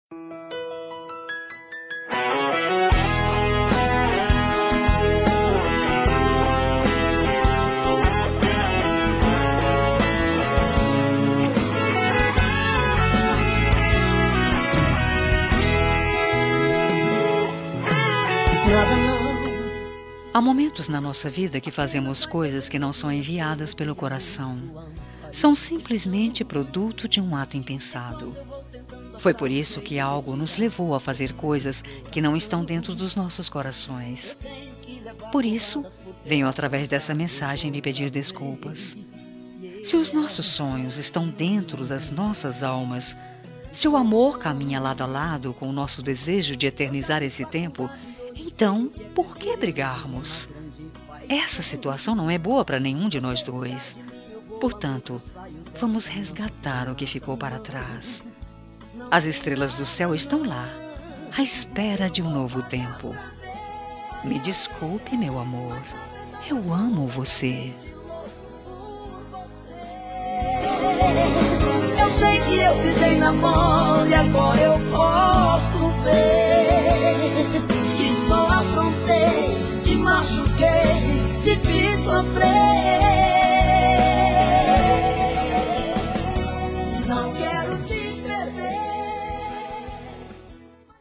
Telemensagem de Desculpas – Voz Feminina – Cód: 346